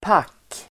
Uttal: [pak:]